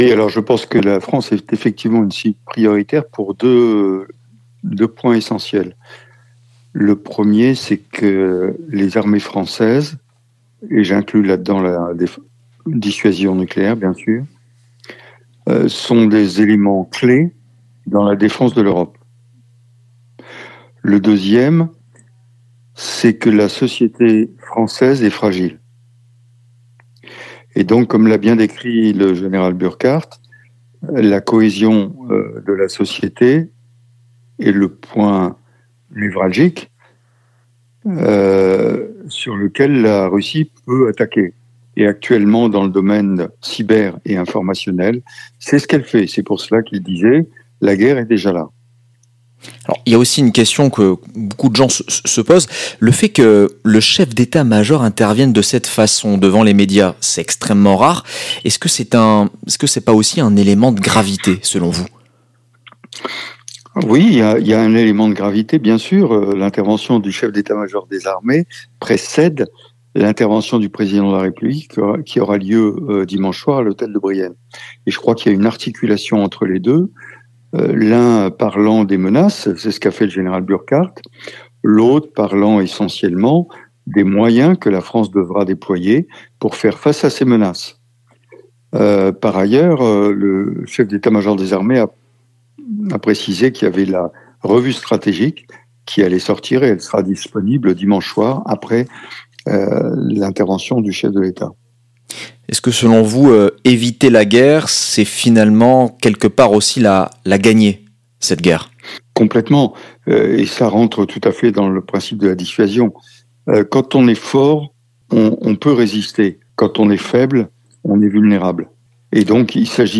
Interview avec le général Dominique Trinquand